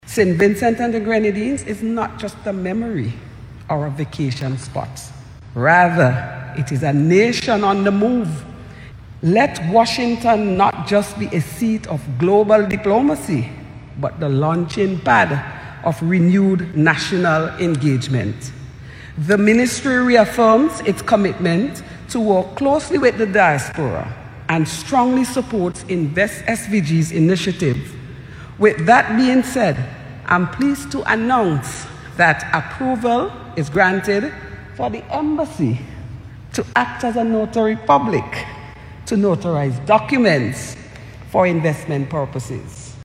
Permanent Secretary, Sandy Peters-Phillips addressed Vincentians at the recently held Diaspora Outreach and Investment Forum in Washington, DC.